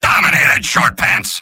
Robot-filtered lines from MvM. This is an audio clip from the game Team Fortress 2 .
Soldier_mvm_dominationscout01.mp3